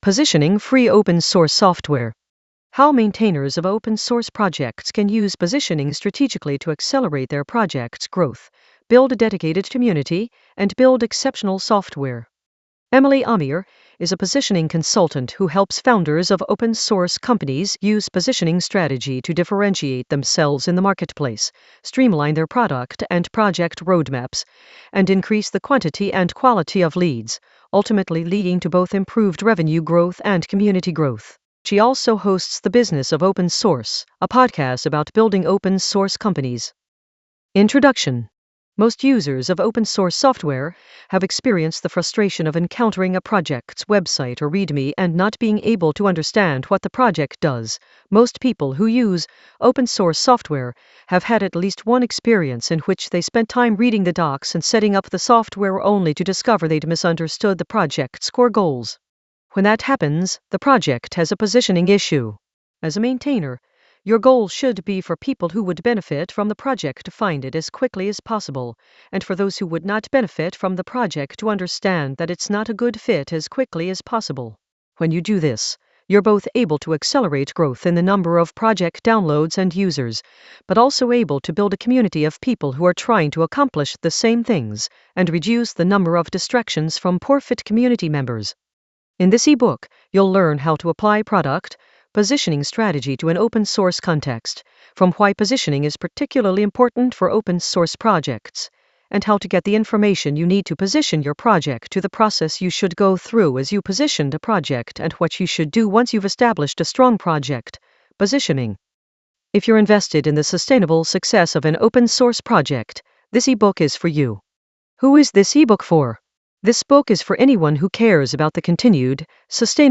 Positioning Free Open Source Software, by Emily Omier - audiobook